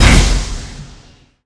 fb_xiaopengzhuang.wav